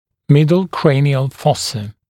[‘mɪdl ‘kreɪnɪəl ‘fɔsə] [‘мидл ‘крэйниэл ‘фосэ] средняя черепная ямка